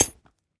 sounds / block / chain / step6.ogg